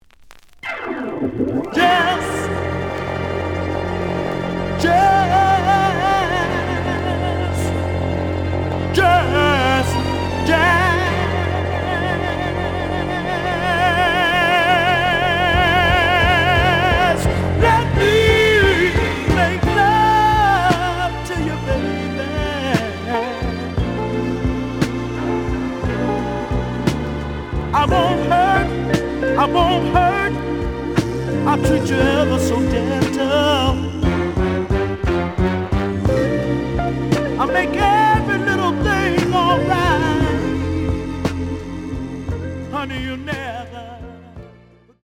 The audio sample is recorded from the actual item.
●Genre: Soul, 70's Soul
Some damage on both side labels. Plays good.)